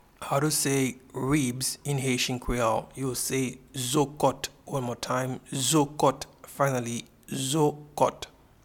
Pronunciation and Transcript:
Ribs-in-Haitian-Creole-Zo-kot.mp3